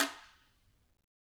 Guiro-Hit_v1_rr2_Sum.wav